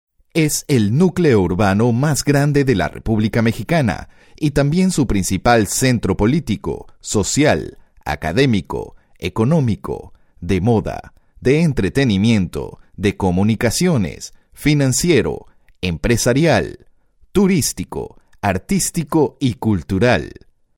Dubbing voice over samples
American Spanish voices